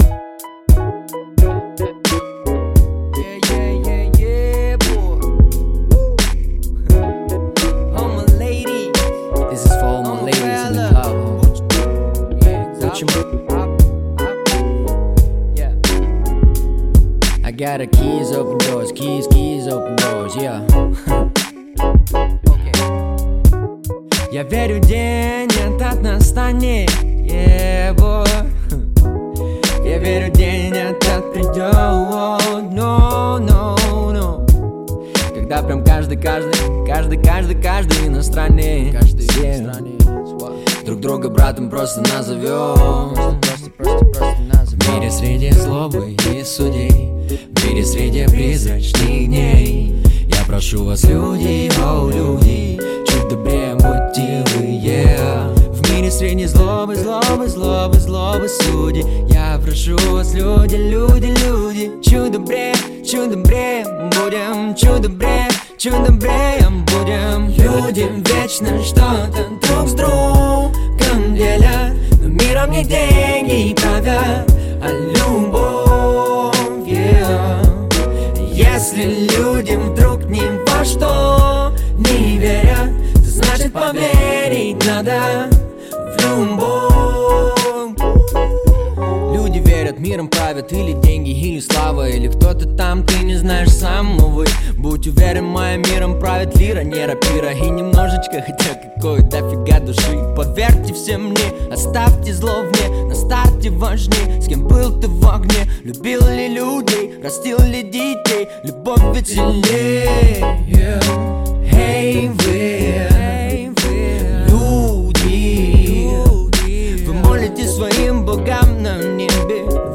Жанр: Русский рэп / R & B